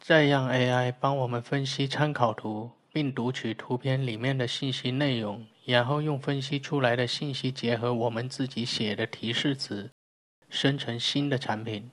Professionell AI-handledningsröst för utbildningsinnehåll
Text-till-tal
Precis artikulation
Instruktionston
Skapad med avancerad syntes erbjuder den en neutral men engagerande ton som bibehåller tittarnas uppmärksamhet.